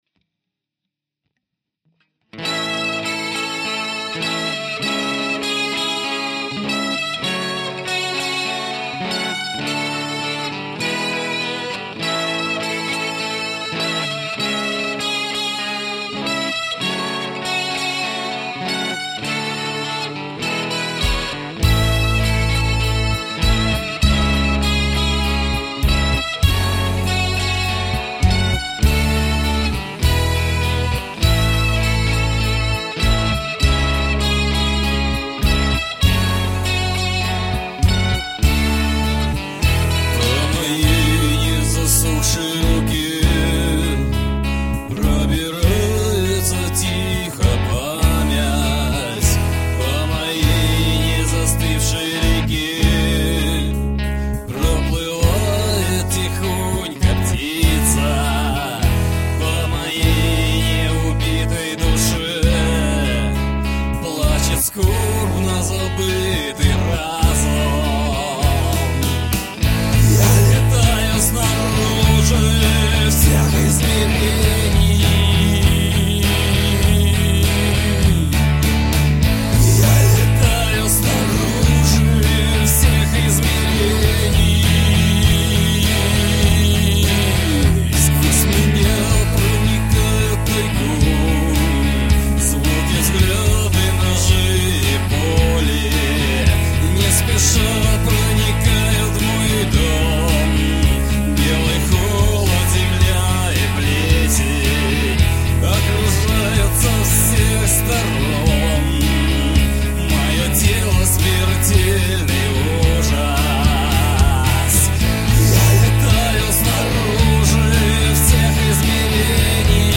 ремэйк.